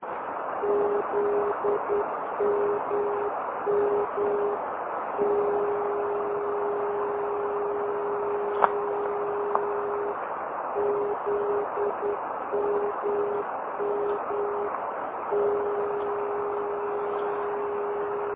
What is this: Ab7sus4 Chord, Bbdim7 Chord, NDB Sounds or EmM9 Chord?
NDB Sounds